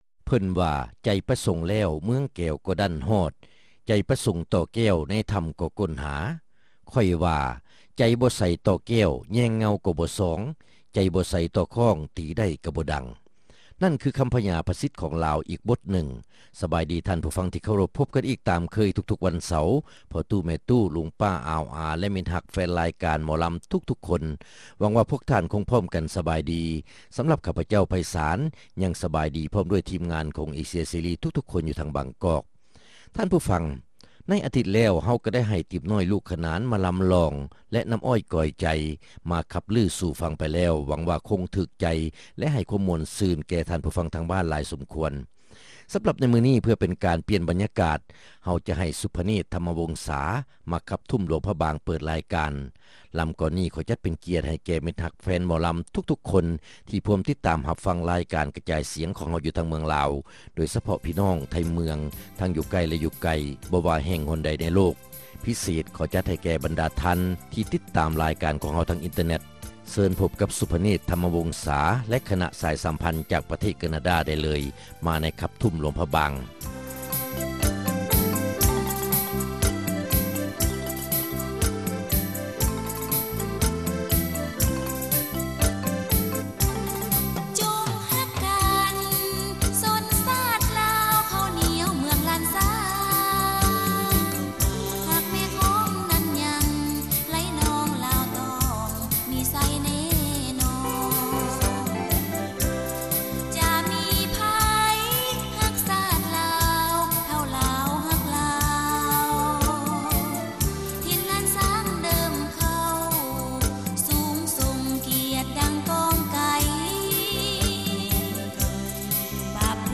ຣາຍການໜໍລຳ ປະຈຳສັປະດາ ວັນທີ 20 ເດືອນ ຕຸລາ ປີ 2005